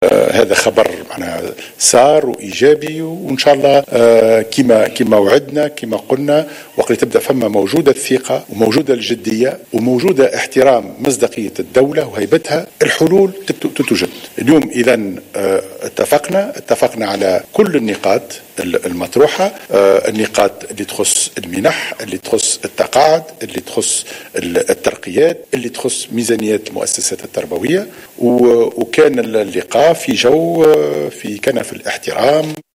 تصريح للتلفزة الوطنية